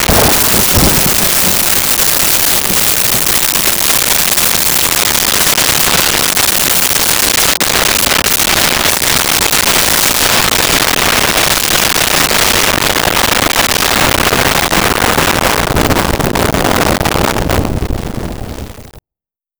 Thunder Clap Loud
Thunder Clap Loud.wav